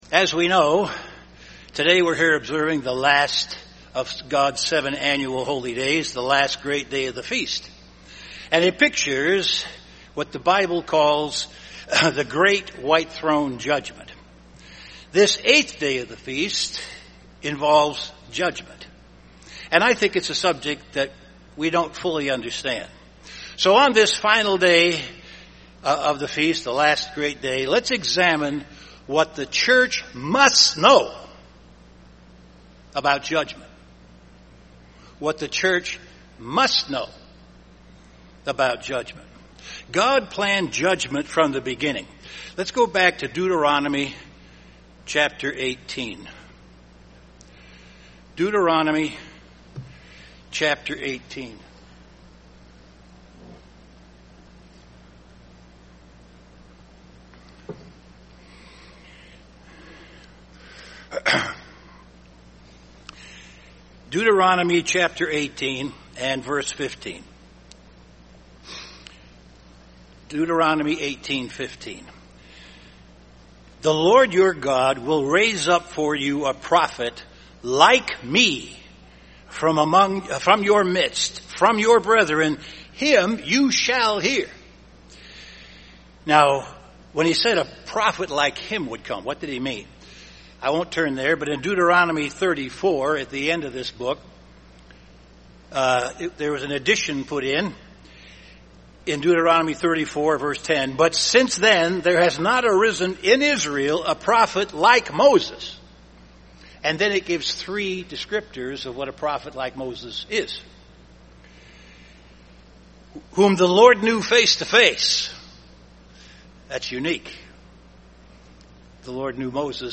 This sermon was given at the Wisconsin Dells, Wisconsin 2013 Feast site.